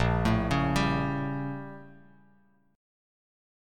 A#mM13 chord